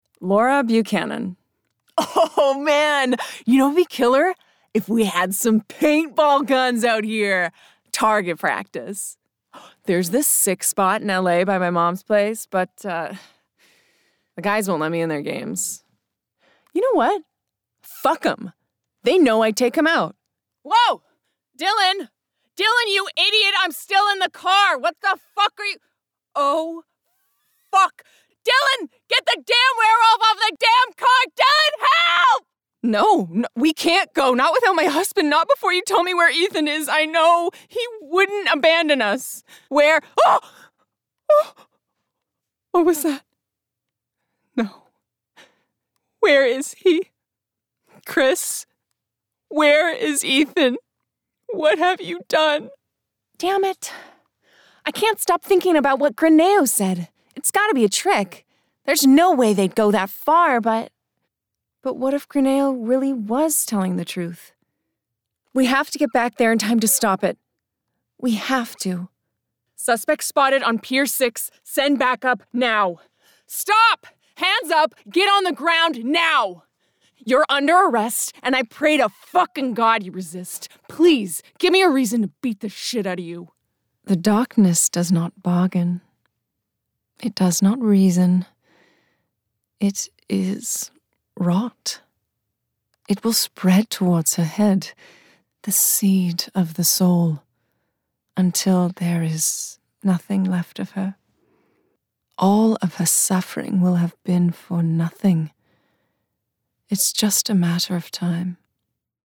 Video Game - EN